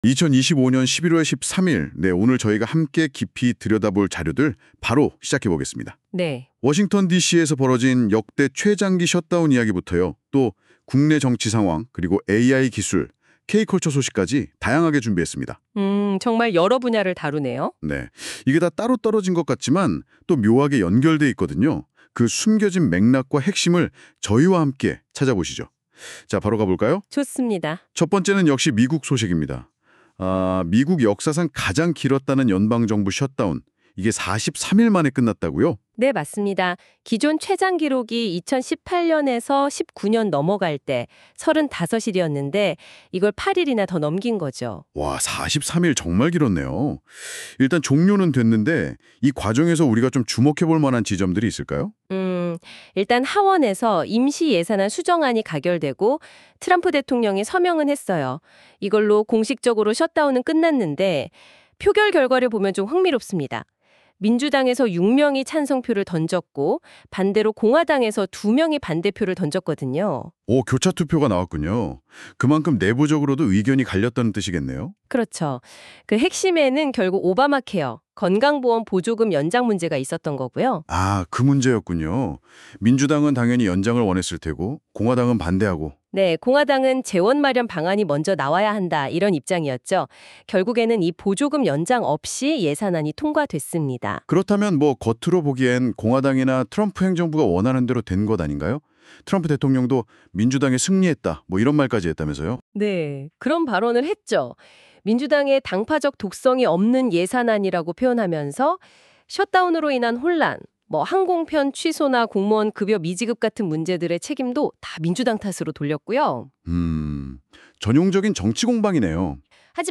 주요 키워드: 셧다운, 트럼프, 노만석, 대장동, AI 검색, 한국 AI 격차, K푸드, 신라면, 이민자 추방, 가톨릭교회 비판 팟캐스트 미국 셧다운 오바마케어 검찰개혁 K-컬처 AI.m4a 노트북 LM을 통해 생성한 팟캐스트입니다.